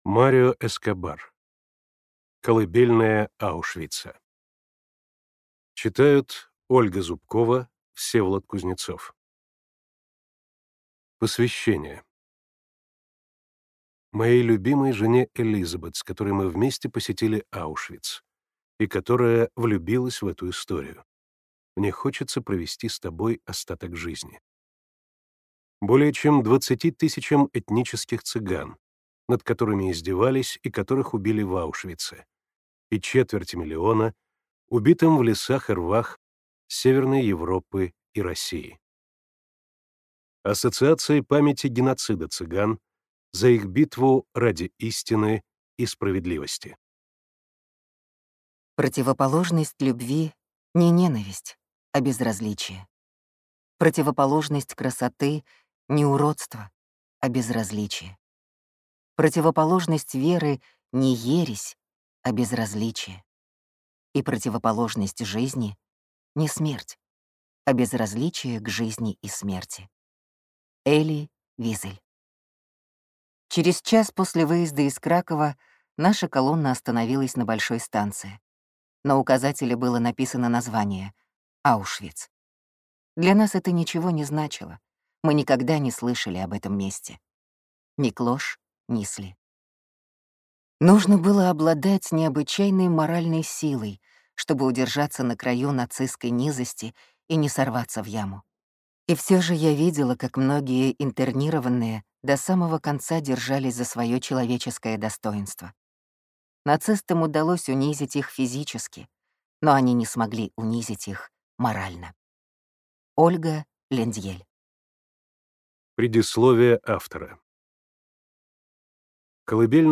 Аудиокнига Колыбельная Аушвица.
Прослушать и бесплатно скачать фрагмент аудиокниги